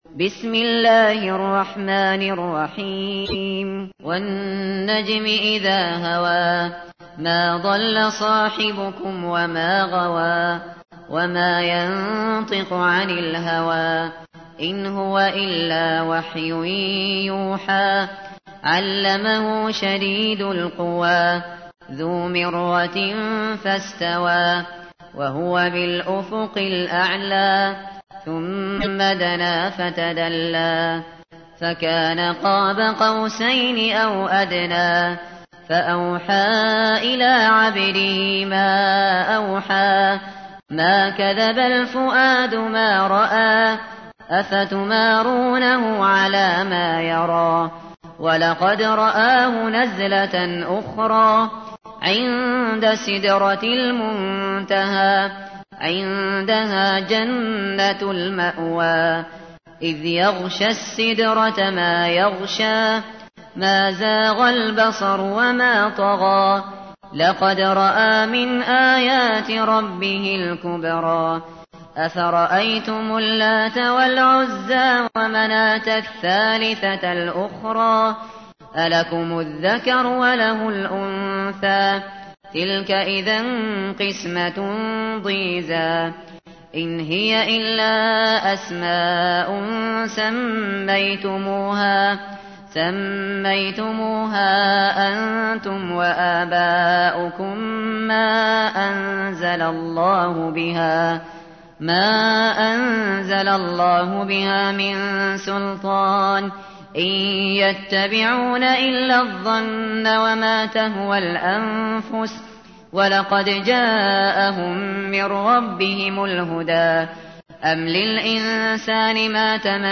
تحميل : 53. سورة النجم / القارئ الشاطري / القرآن الكريم / موقع يا حسين